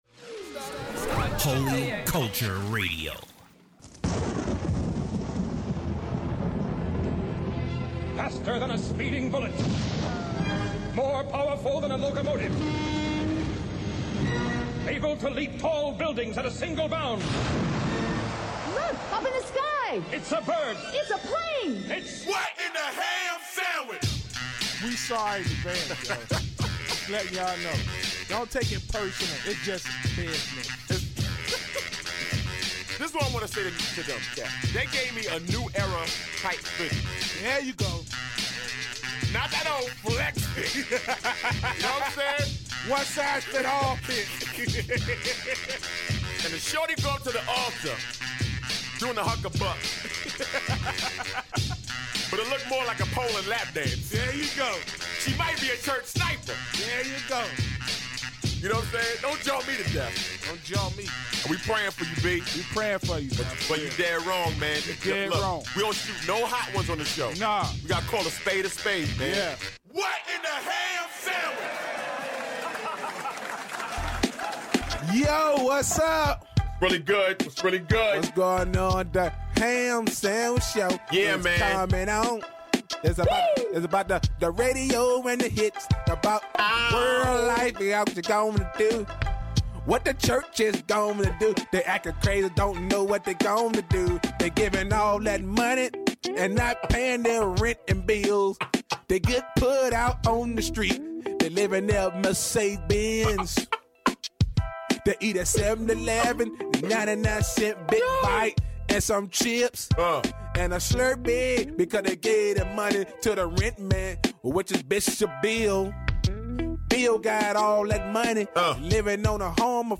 The Ham Team Celebrates their 150th show by taking some of the funniest freestyles, humorous conversations, and strongest points within their first 75 shows and combined them for the ultimate highlight reel...Enjoy!